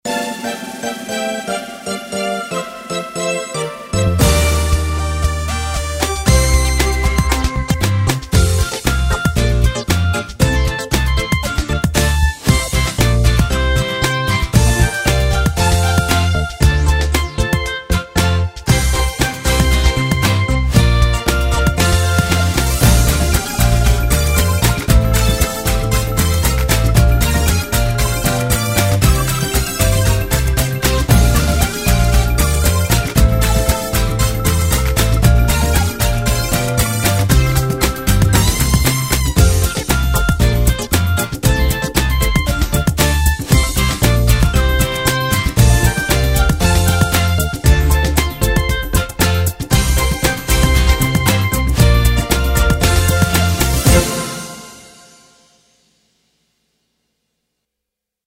鈴鈴鈴(純伴奏版) | 新北市客家文化典藏資料庫